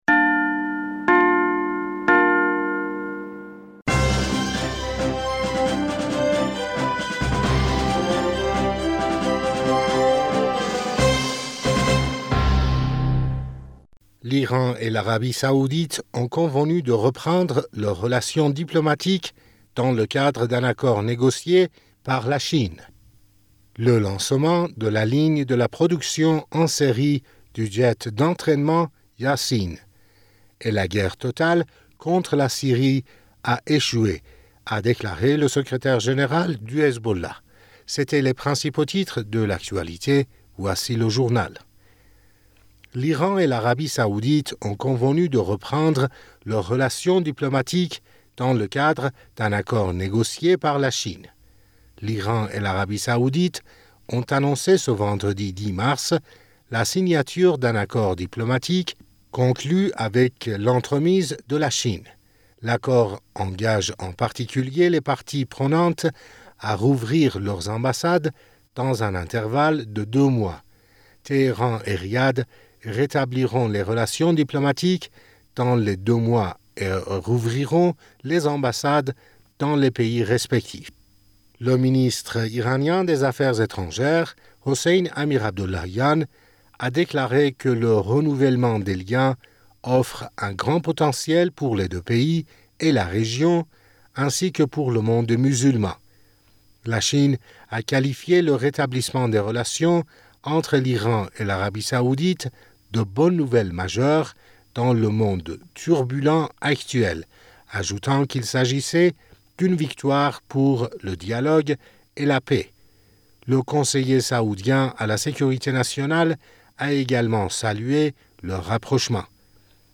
Bulletin d'information du 11 Mars